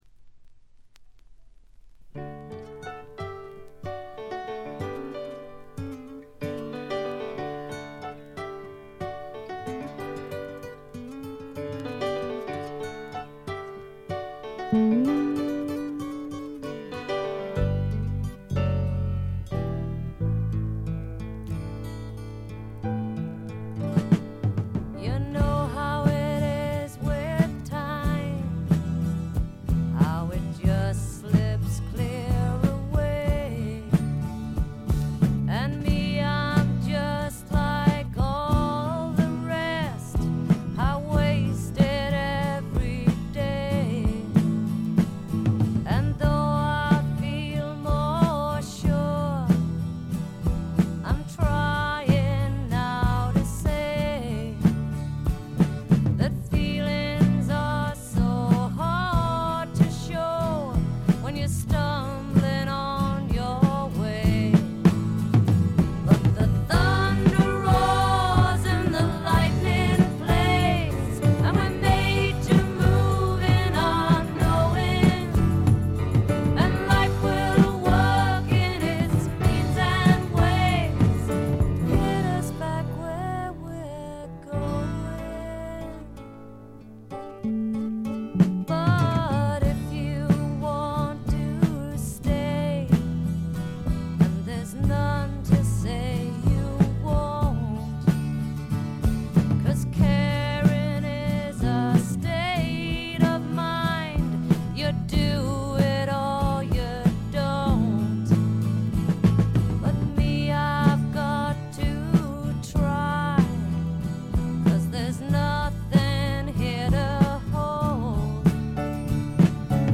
静音部での細かなチリプチ。
試聴曲は現品からの取り込み音源です。